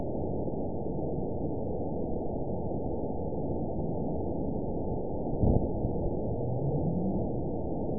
event 911911 date 03/11/22 time 17:04:03 GMT (3 years, 2 months ago) score 9.56 location TSS-AB08 detected by nrw target species NRW annotations +NRW Spectrogram: Frequency (kHz) vs. Time (s) audio not available .wav